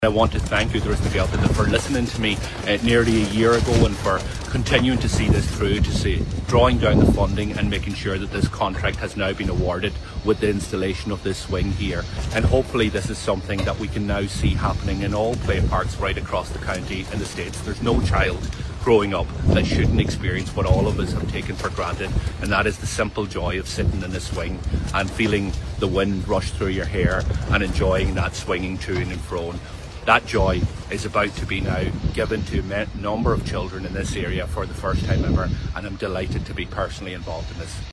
Donegal Deputy Pearse Doherty says Donegal County Council should now look at providing similar facilities at every playground in the county.